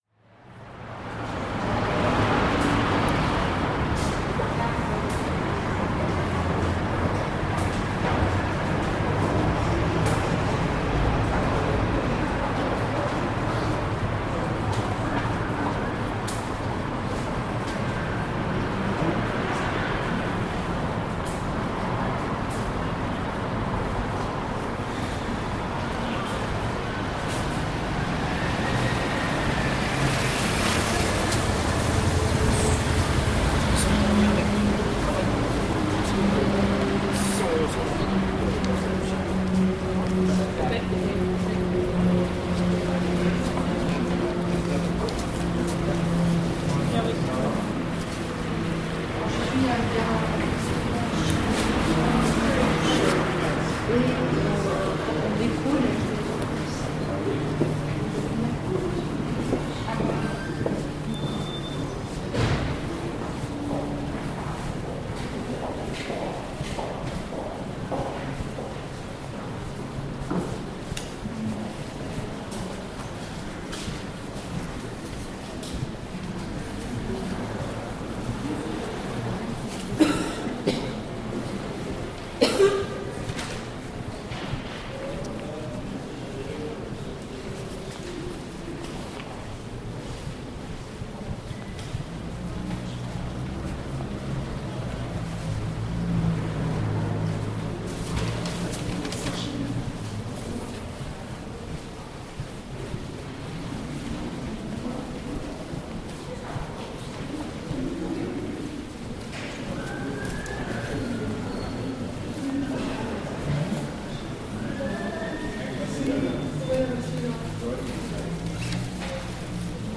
Passage du Grand Cerf, Paris